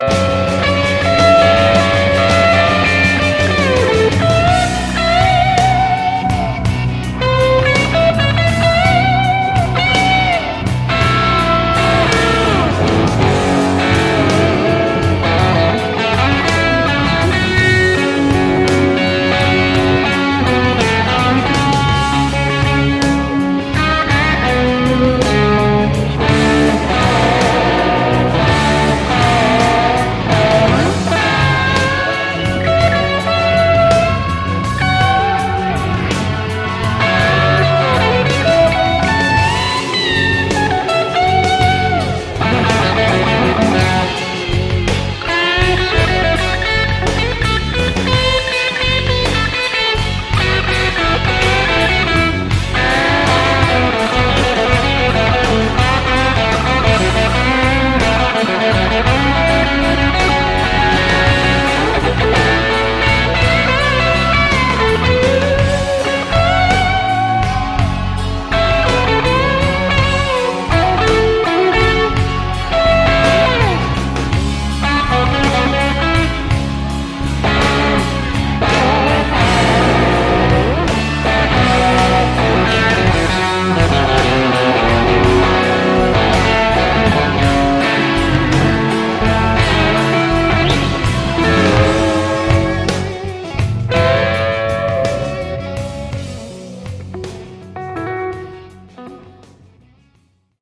Tausta alennettu Gee.
- soita soolosi annetun taustan päälle